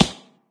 plasticgrass.ogg